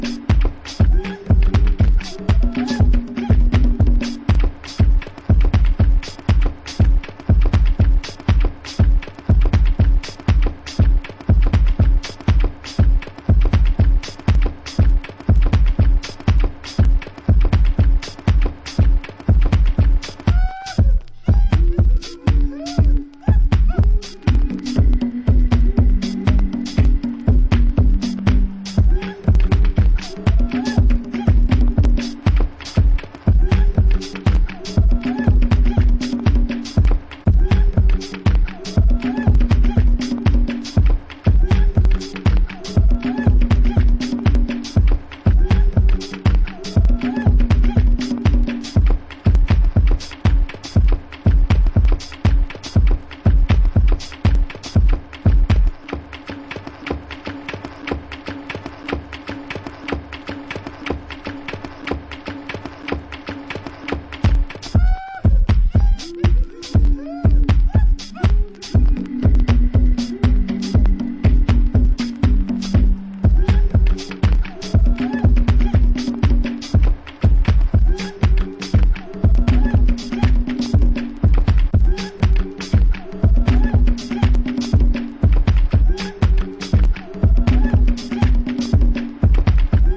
Deep African drums, Rhodes b... more...
House